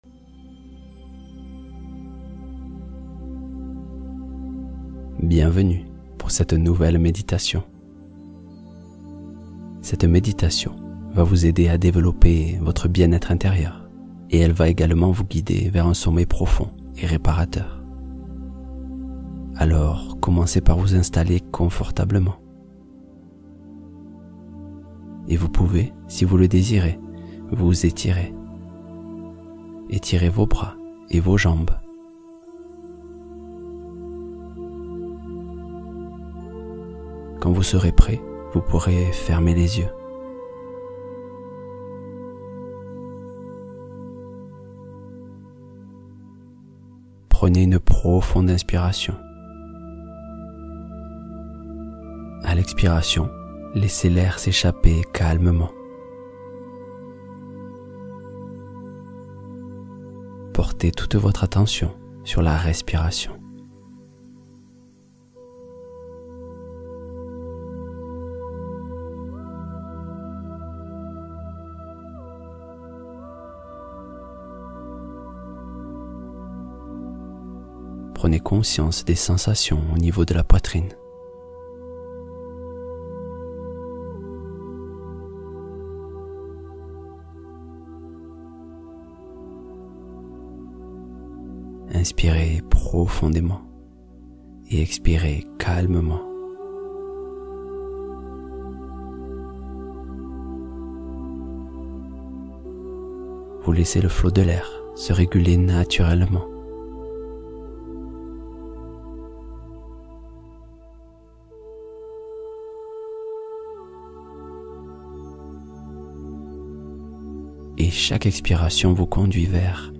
Visualisation guidée — Explorer et soutenir la confiance intérieure